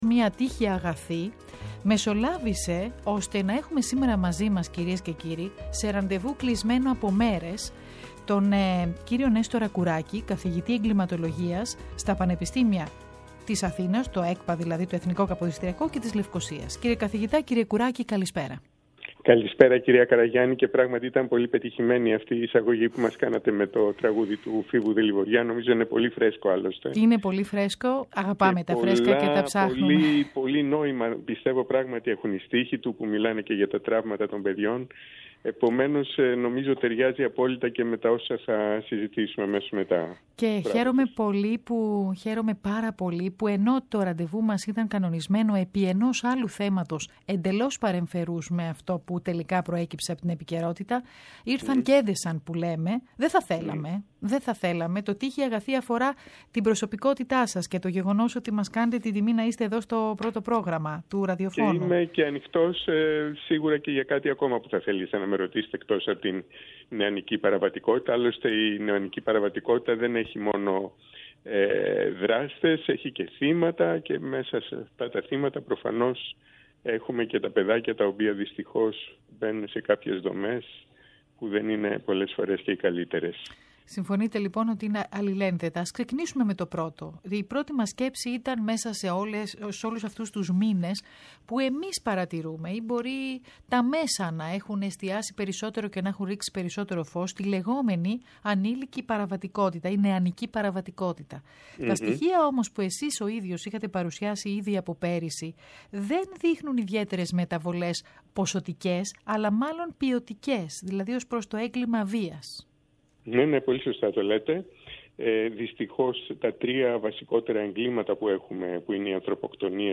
Μια συζήτηση